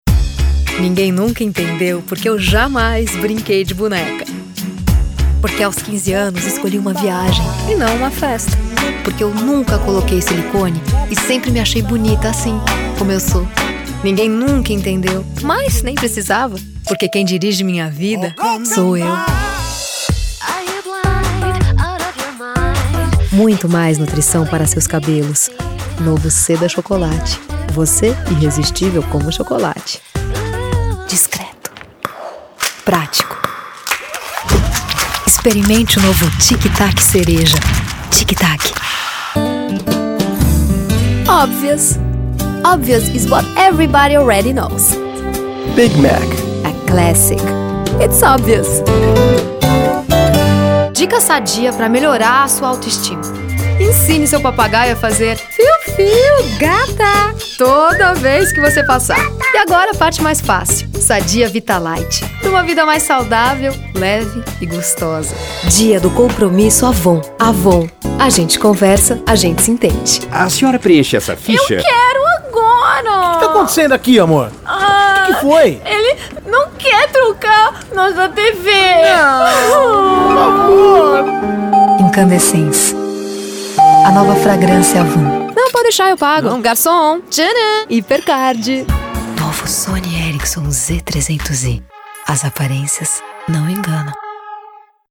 Friendly, honest, natural, Brazilian voice
Sprechprobe: Werbung (Muttersprache):